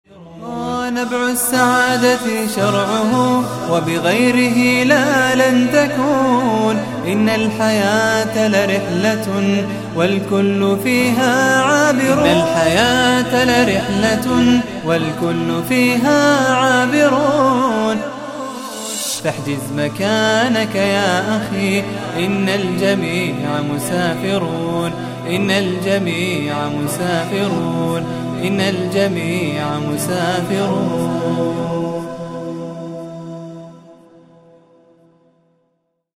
من هو هذا المنشد :